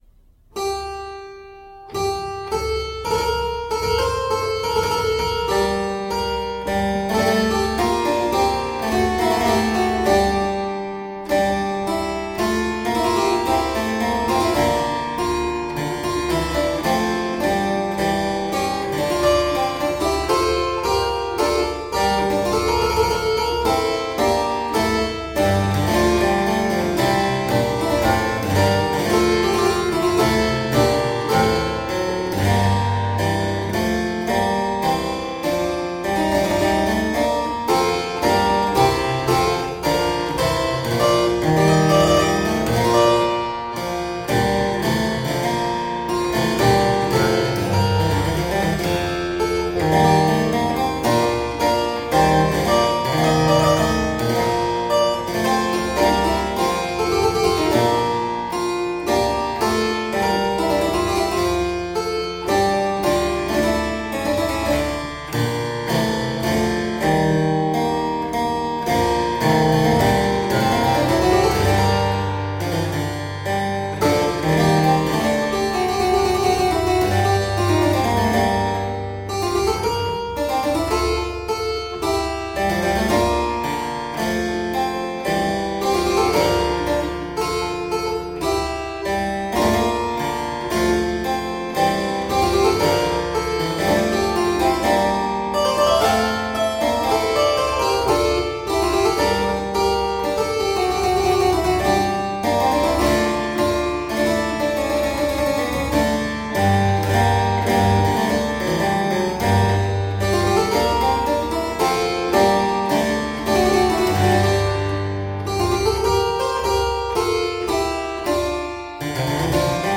Solo harpsichord music
Classical, Baroque, Instrumental Classical
Harpsichord